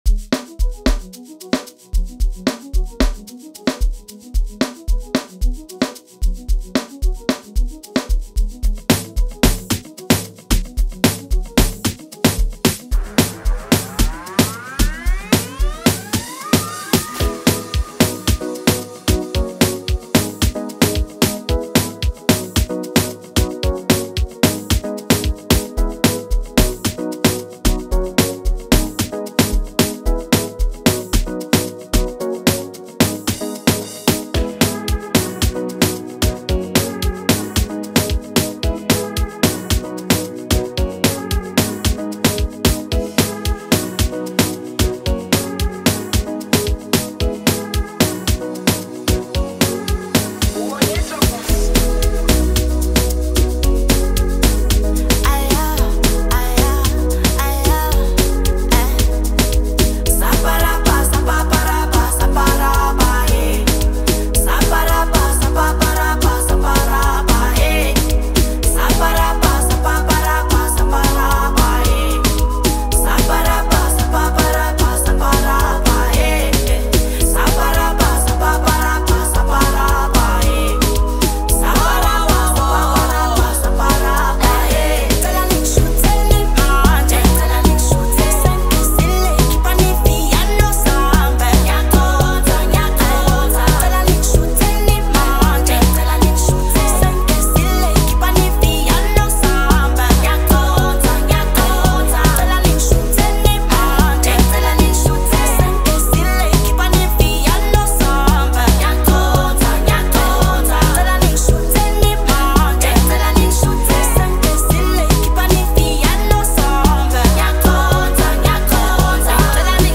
amapiano
expressive vocals
Don’t miss out on this soulful amapiano anthem.